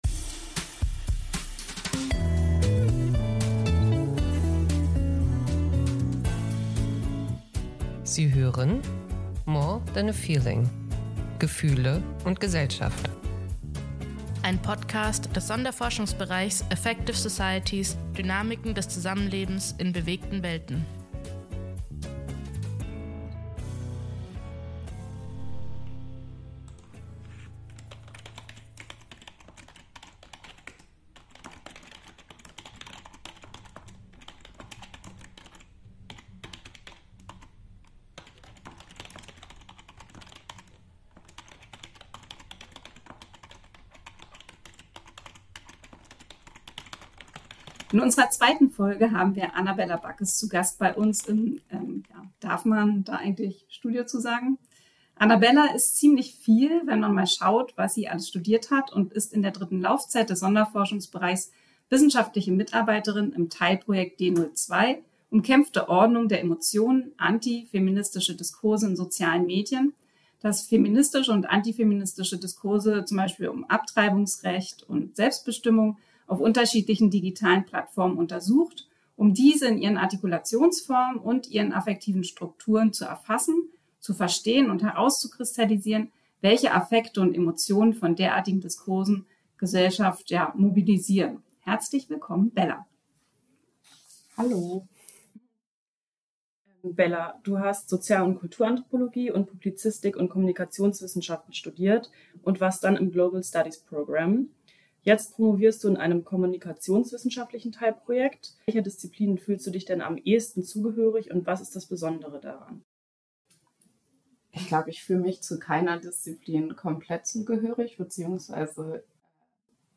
In dieser Folge erfahrt ihr, wie es ist, als Kommunikationswissenschaftlerin durch TikTok zu scrollen und welche Werkzeuge man braucht, um die Inhalte zu analysieren. Ein Gespräch